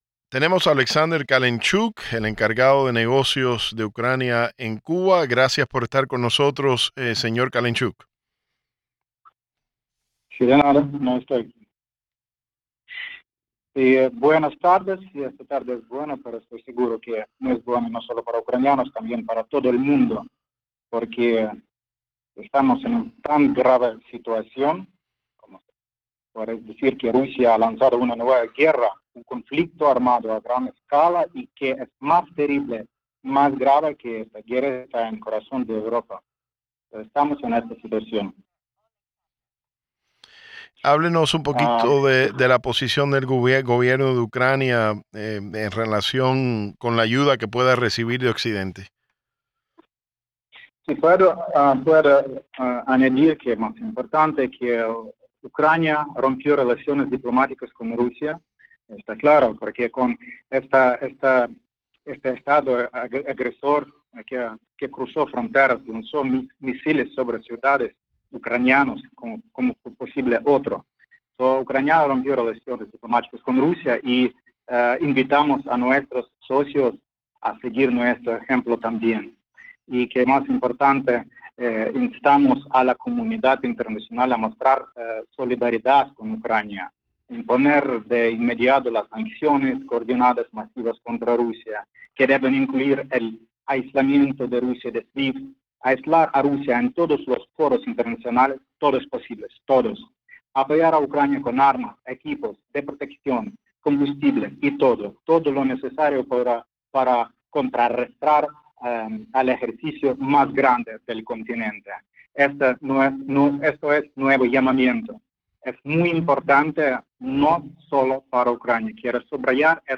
El encargado de negocios de Ucrania en Cuba, Oleksandr Kalinchuk, confirmó que su país rompió relaciones diplomáticas con Rusia y pidió la solidaridad internacional para el pueblo ucraniano y el aislamiento de Rusia, en entrevista exclusiva para Radio Martí.
Radio Televisión Martí entrevista al encargado de negocios de Ucrania en La Habana